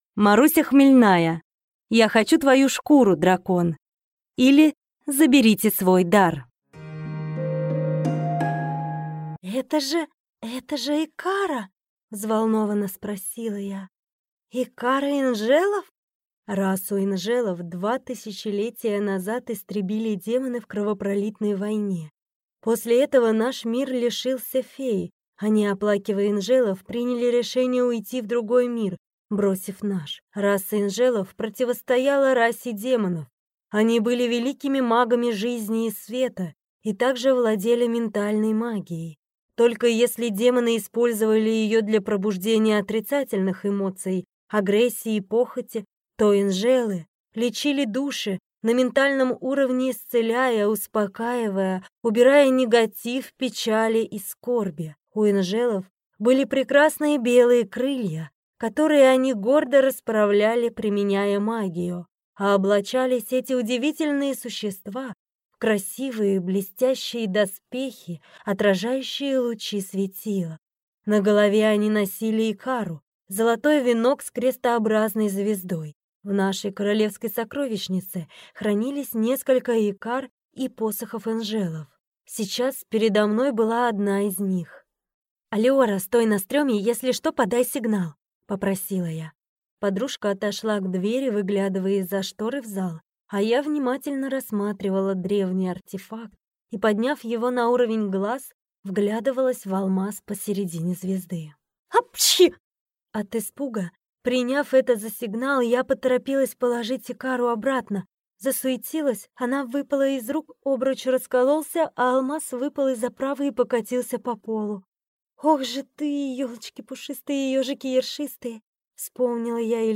Аудиокнига Я хочу твою шкуру, дракон! или Заберите свой дар!
Прослушать и бесплатно скачать фрагмент аудиокниги